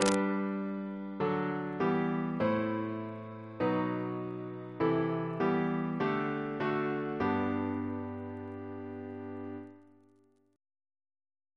Single chant in G Composer: Sir George Elvey (1816-1893), Organist of St. George's Windsor; Stephen's brother Reference psalters: OCB: 110; PP/SNCB: 37